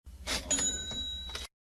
moneyCollect.ogg